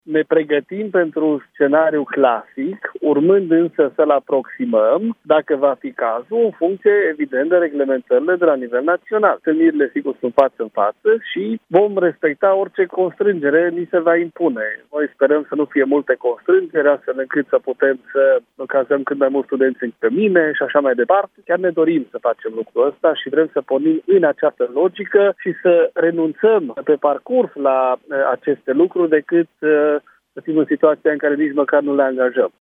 Este deocamdată scenariul pe care se lucrează, însă bs-ar putea modifica în funcție de situația epidemiologică, spune rectorul Universității Babeș – Bolyai din Cluj – Napoca, Daniel David.